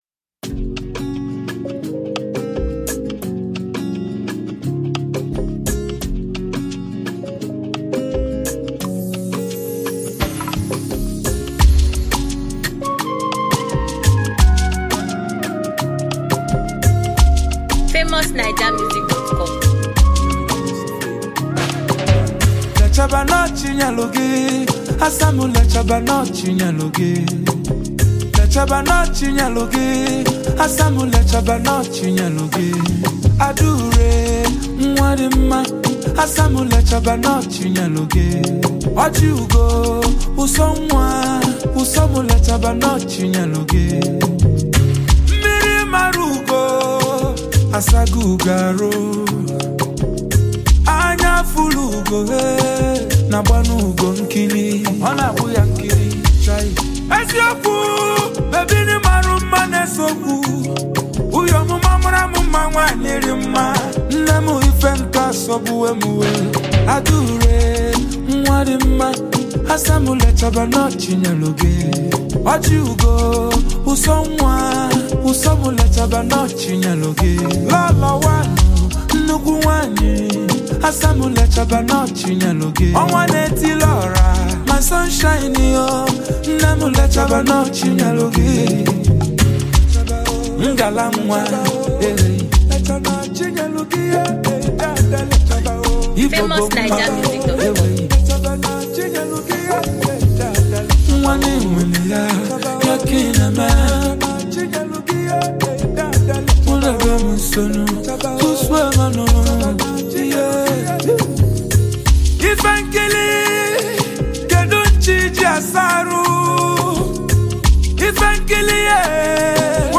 The catchy melody of this track will live with you forever.